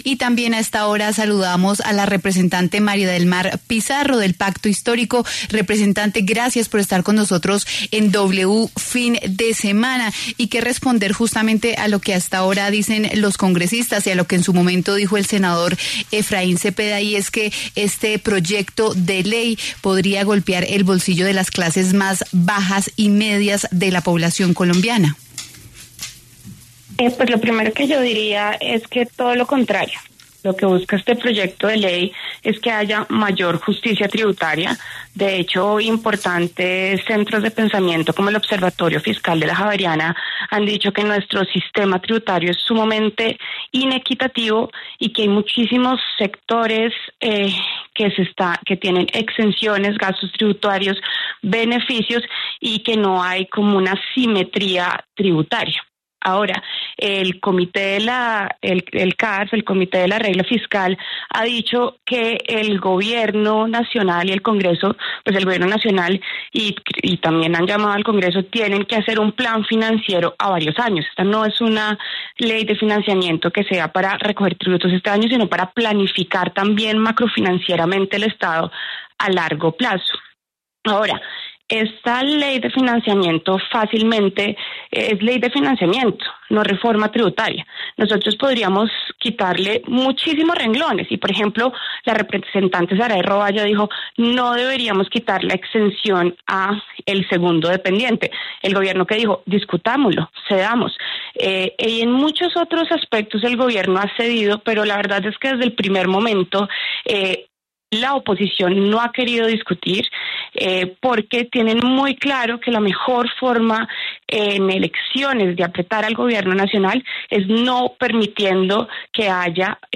María del Mar Pizarro, representante del Pacto Histórico, dialogó con W Fin De Semana acerca del posible hundimiento de la ley de financiamiento del Gobierno Petro en el Congreso.